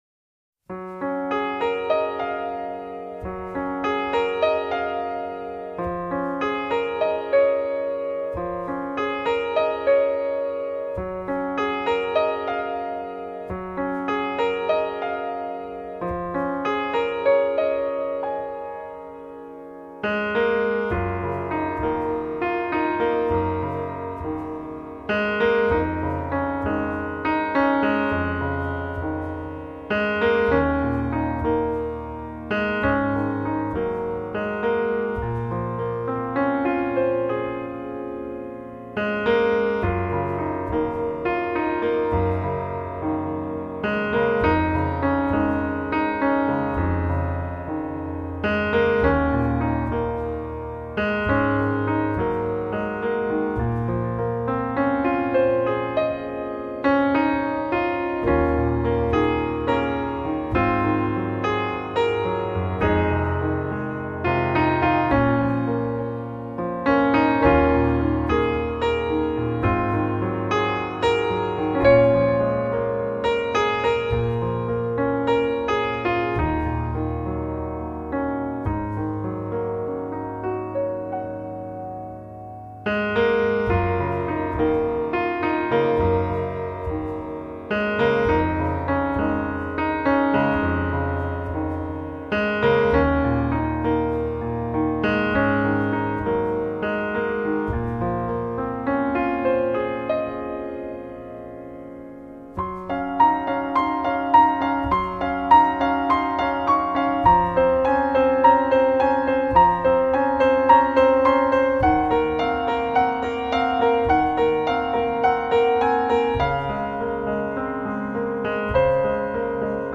聴く者を安らかな瞑想へと誘ってくれる。
钢琴合集